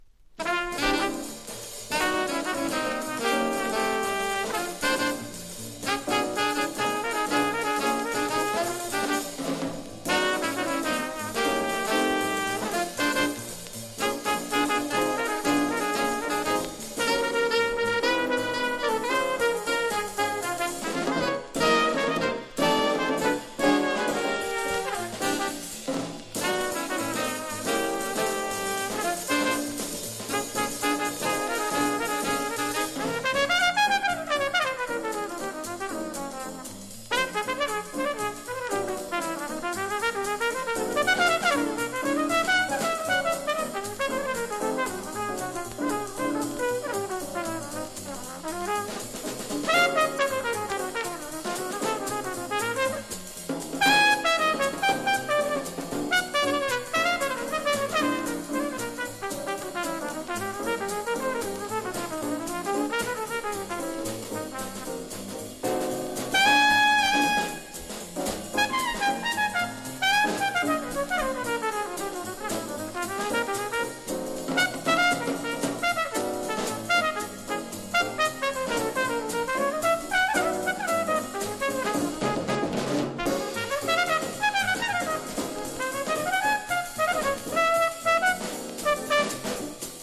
ハードバップ
（1984年・MONO・帯・解説付き）